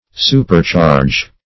Supercharge \Su`per*charge"\, n. (Her.)